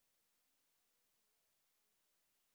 sp29_street_snr20.wav